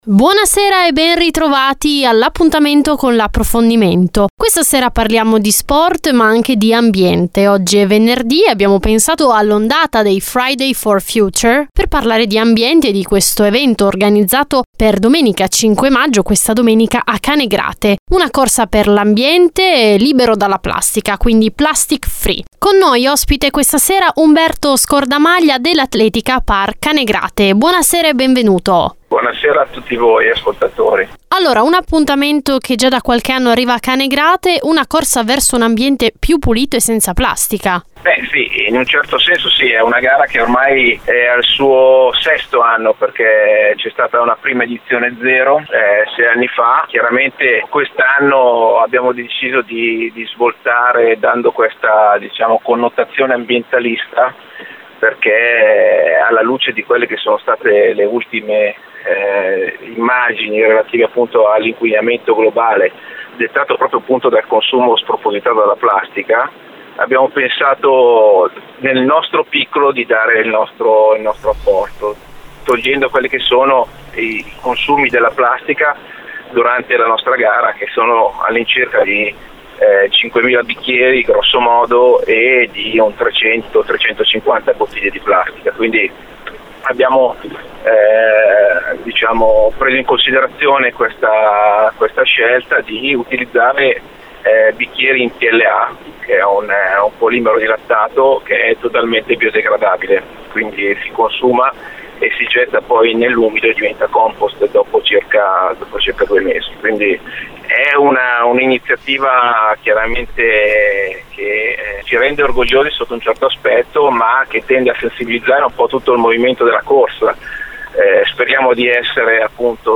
l’intervista integrale su Radio Marconi